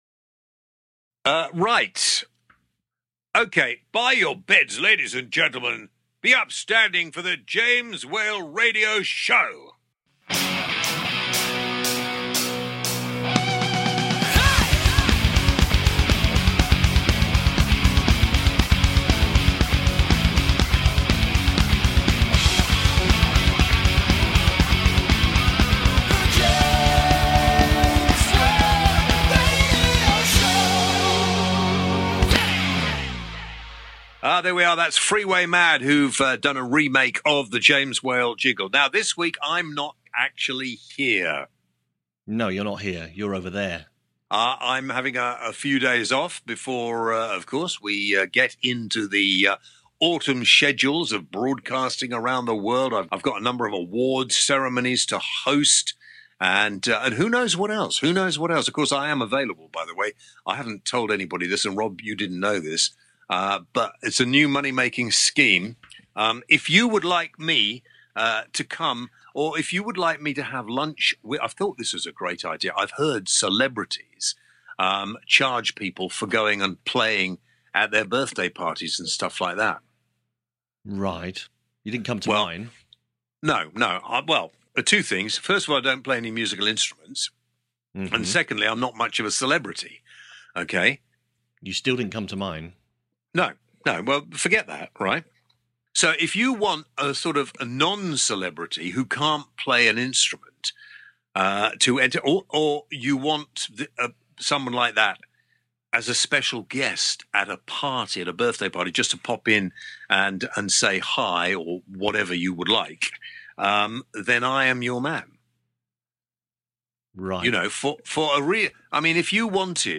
On the show, James has a new money making venture, an old interview from the archives with the late great Spike Milligan on depression in its entirety.